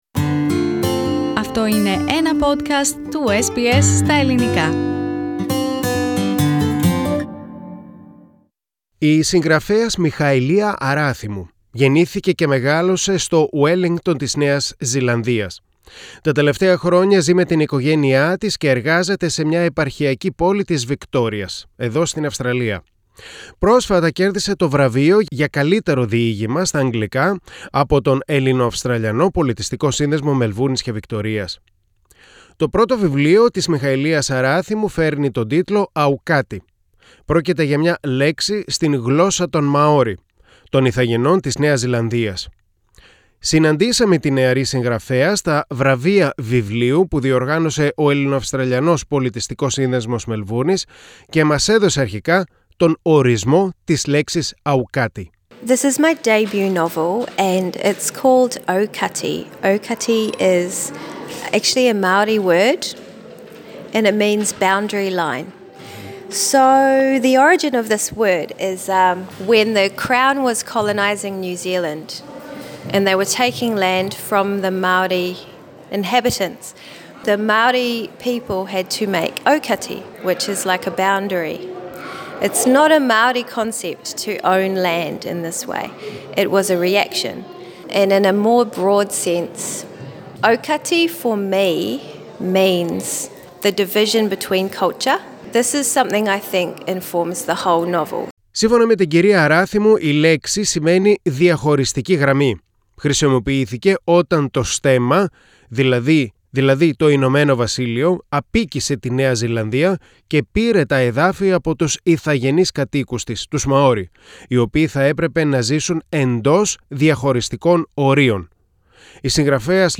at SBS Radio Studios in Melbourne.